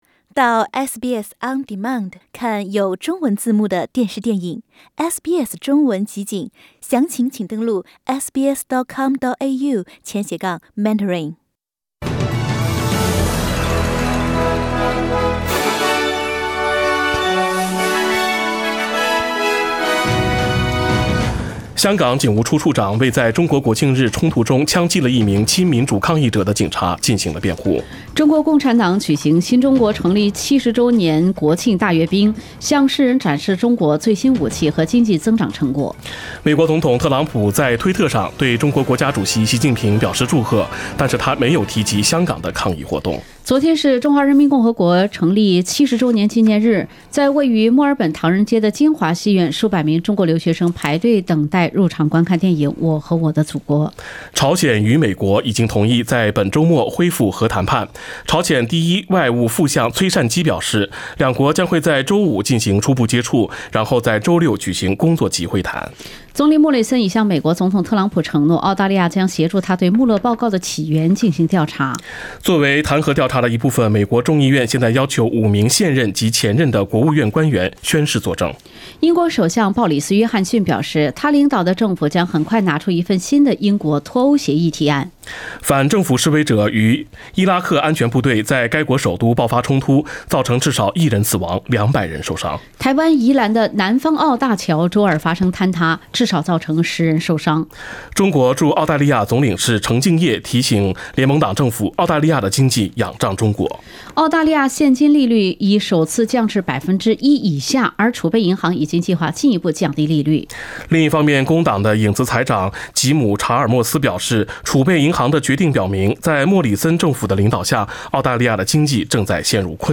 SBS普通话新闻快讯